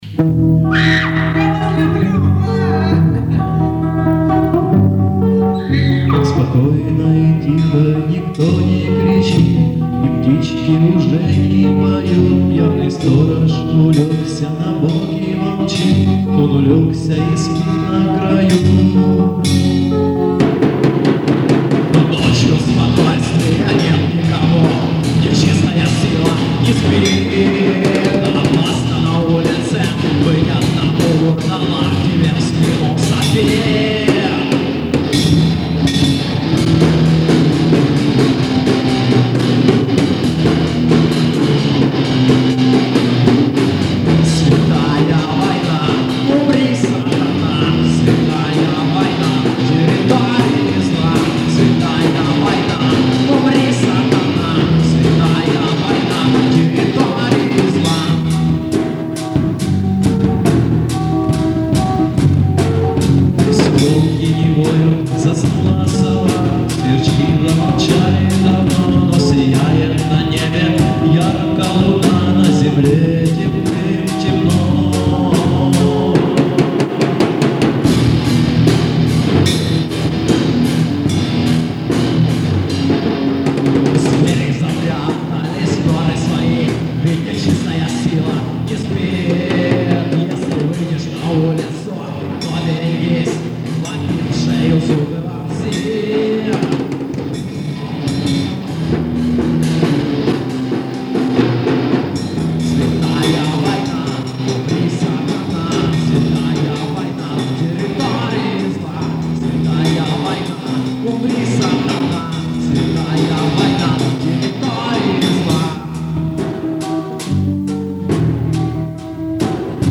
На записи (ужасного магнитофонного качества) репетиция вроде бы 2003 года.
Рубрика: Поезія, Авторська пісня